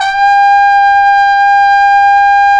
Index of /90_sSampleCDs/Roland L-CD702/VOL-2/BRS_Cup Mute Tpt/BRS_Cup Mute Dry